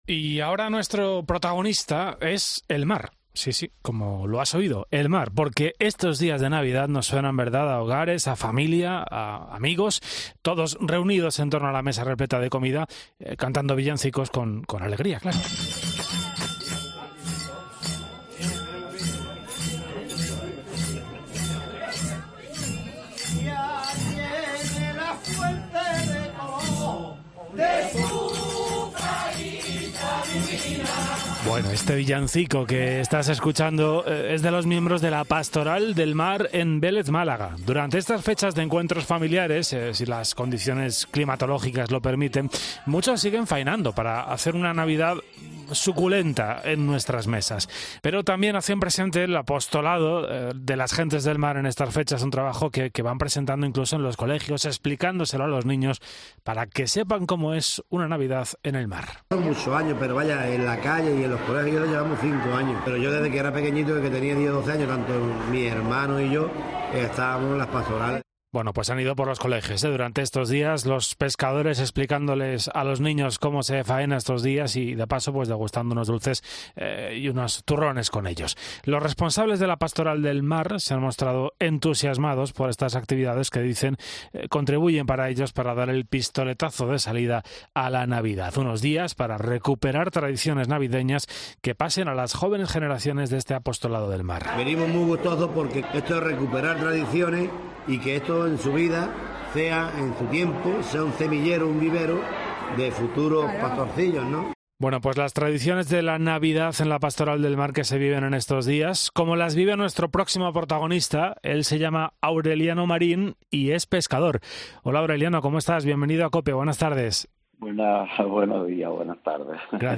ctv-ome-n14-entrev-mar-23-12